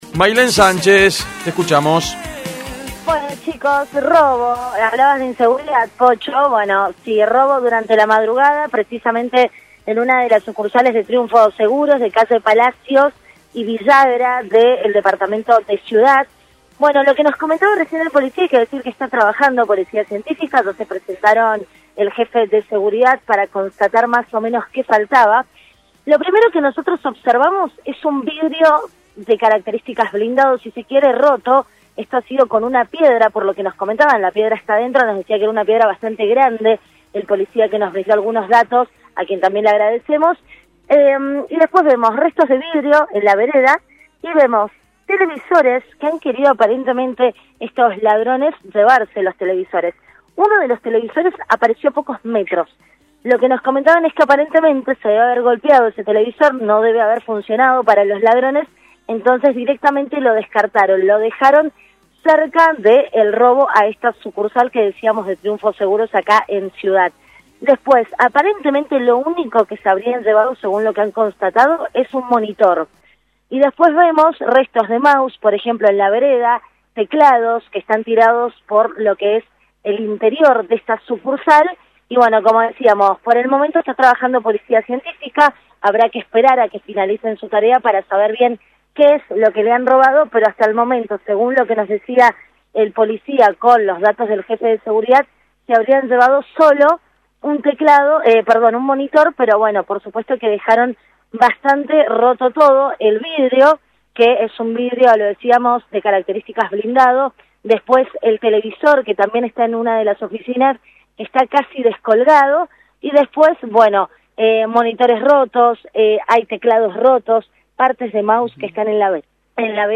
LVDiez - Radio de Cuyo - Móvil de LVDiez - Robo en aseguradora de calle Palacios y Villagra de Ciudad